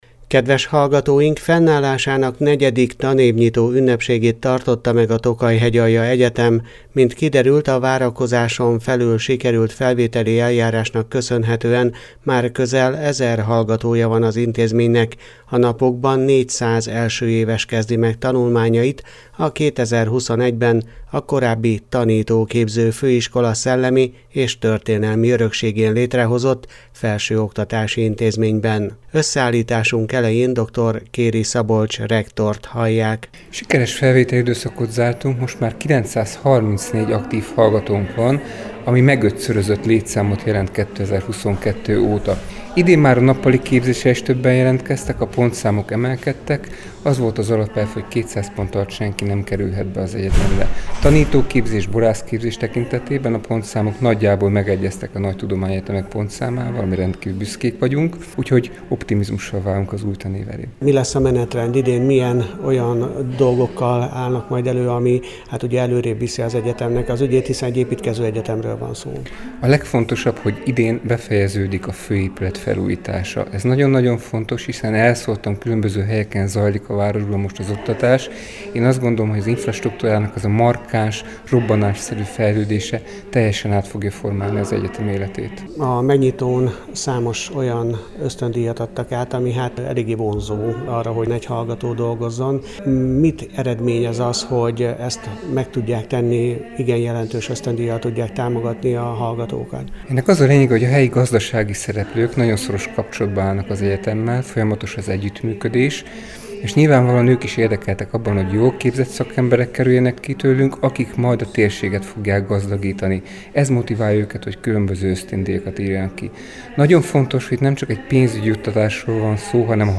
Fennállásának negyedik tanévnyitó ünnepségét tartotta meg a Tokaj-Hegyalja Egyetem